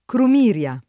[ krum & r L a ]